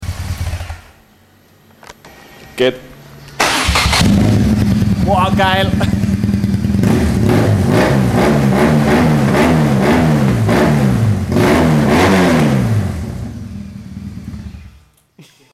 Original nur halt ohne Auspuff:
ohne Auspuff
auspuff_ohne.mp3